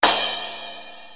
RX7-CRASH.wav